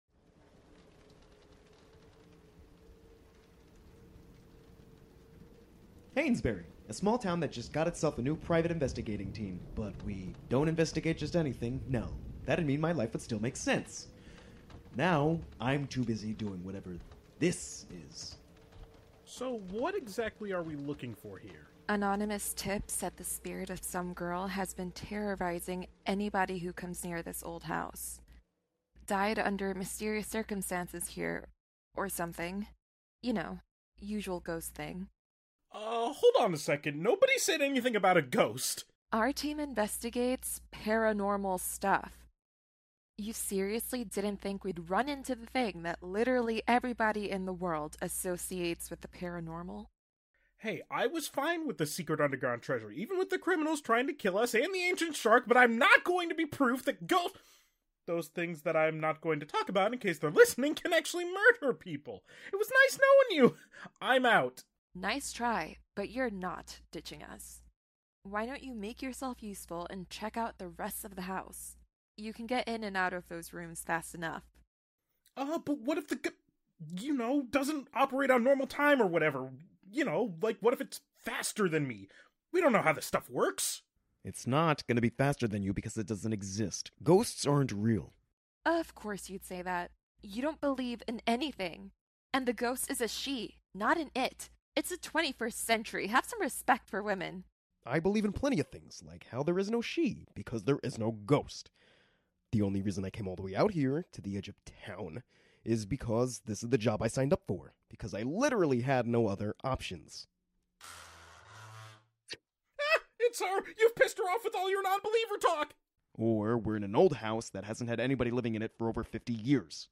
*Disclaimer - The first 6 episodes of our show were when we were still finding our audio footing and our voice; we are a living production, after all!